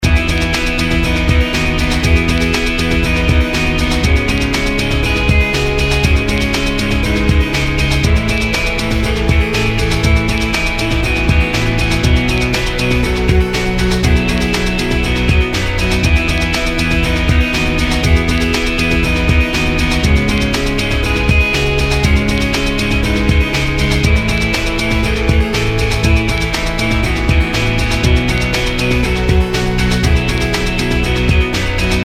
A poppy adventurous loop with a bunch of guitars, synths, and a drum sample.
[-[[ＡＩＮ'Ｔ ＮＯＴＨＩＮ' ＬＩＫＥ Ａ ＫＩＣＫＩＮ' ＧＲＯＯＶＥ]]-] 96 Views 0 Favorites 0 Comments General Rating Category Music / Pop Species Unspecified / Any Gender Any Size 120 x 120px File Size 500.9 kB Keywords adventurous nostalgic happy pop rock orchestral